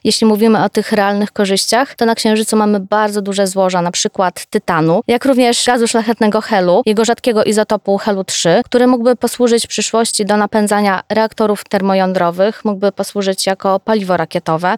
Całość rozmowy dostępna jest na platformach podcastowych: